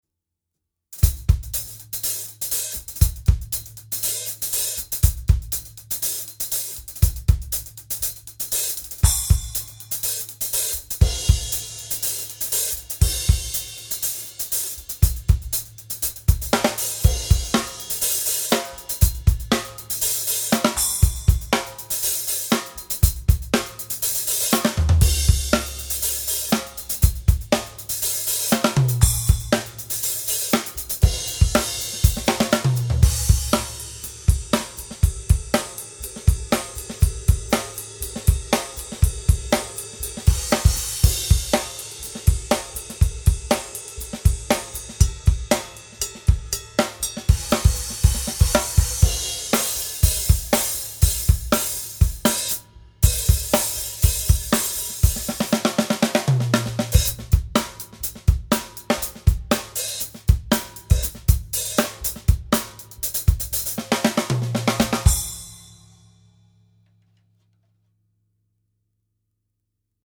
Meinl-Classics-Custom-series.mp3